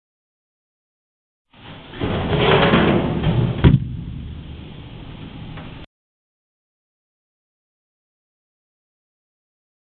Звук падения